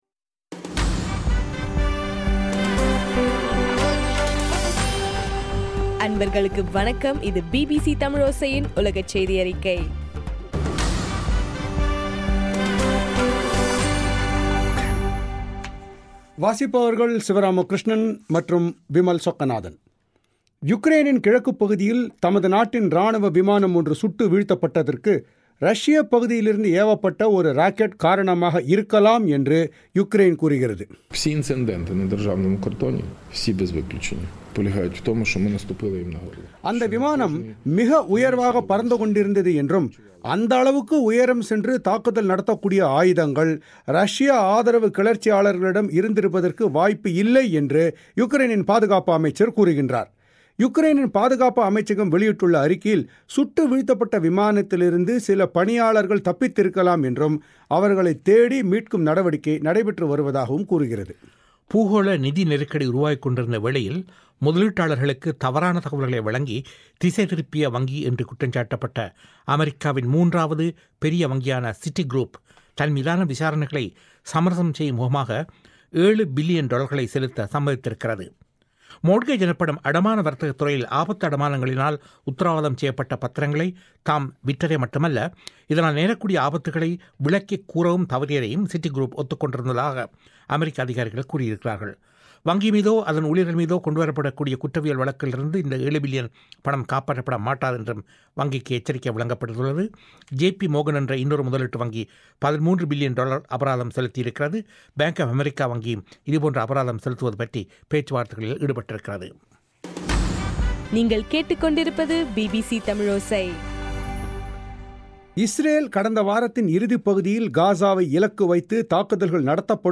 இன்றைய (ஜூலை 14) பிபிசி தமிழோசை செய்தியறிக்கை